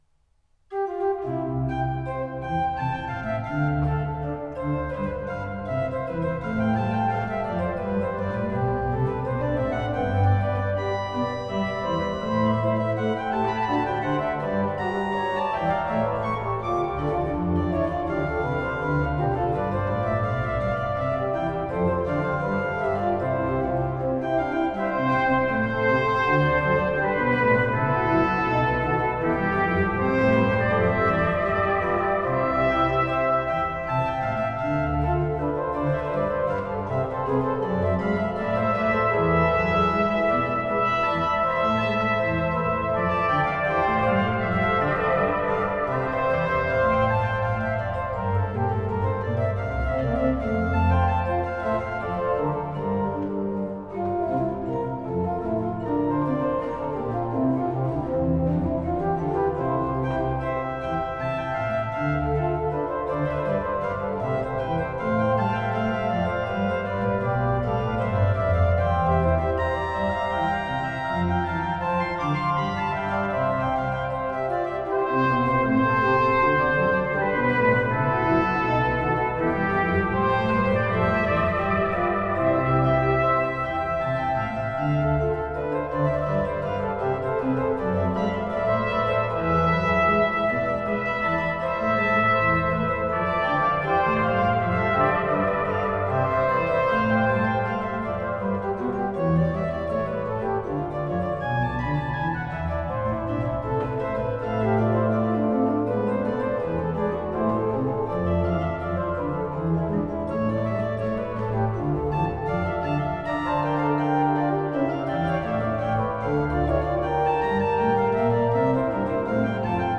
barocke Naturtrompete
Silbermann-Orgel